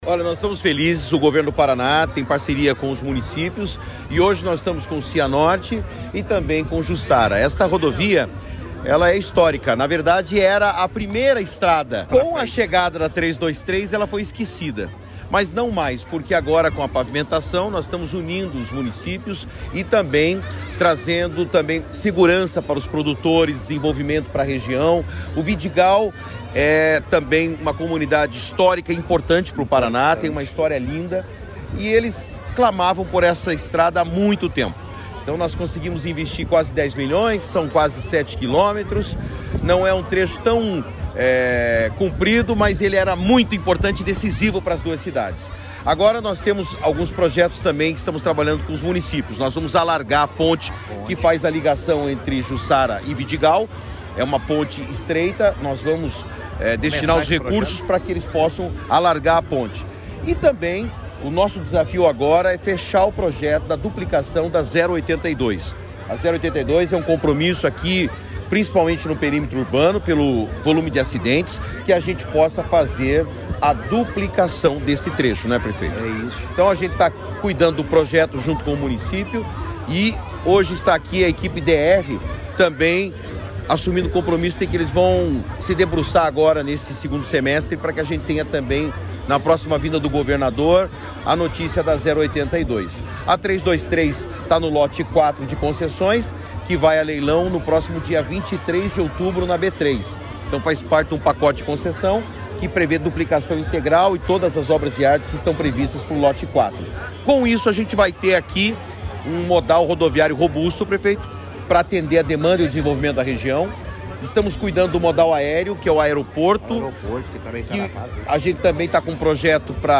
Sonora do secretário de Infraestrutura e Logística, Sandro Alex, sobre a pavimentação que liga distrito de Cianorte a Jussara